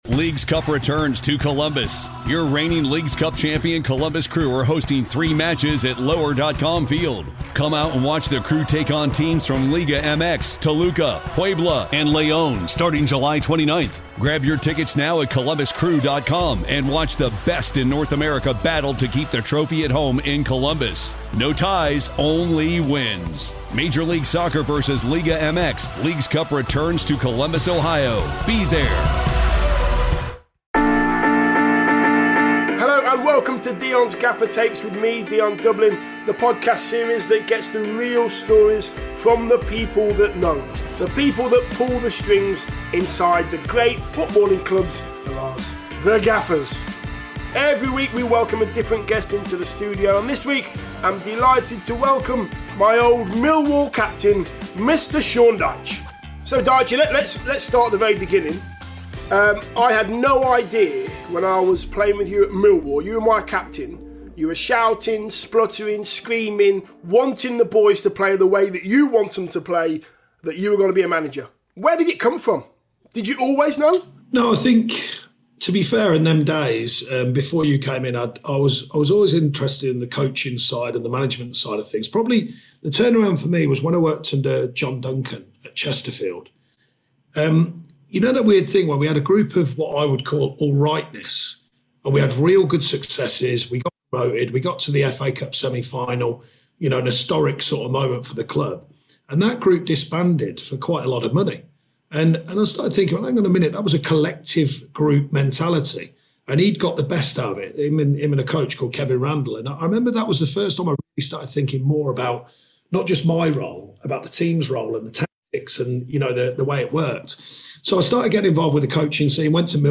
Sean Dyche is playing at the top of his game at Burnley, and while a series of factors have conspired against him this season, and see his side in the bottom six rather than the top six at present, you know that if anyone will turn their form around it’ll be the gravel voiced Mr Dyche!